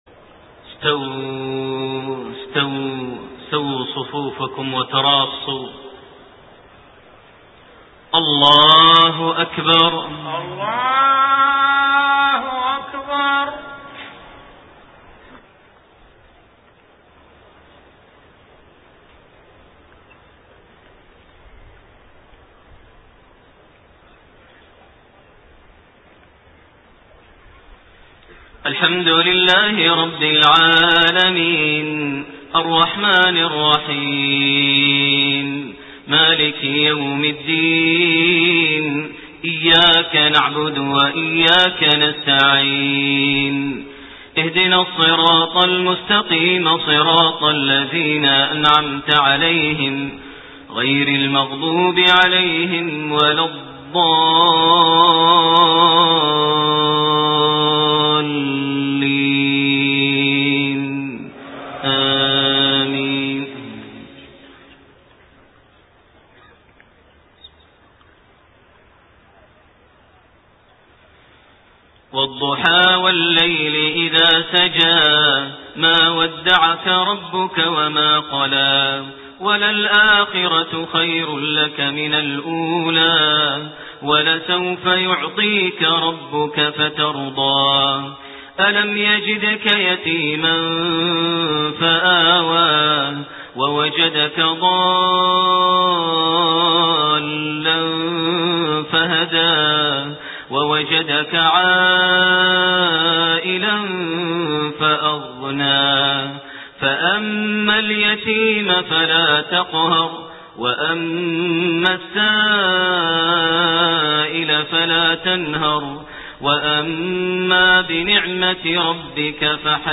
صلاة المغرب 1-8-1428 سورتي الضحى و العصر > 1428 هـ > الفروض - تلاوات ماهر المعيقلي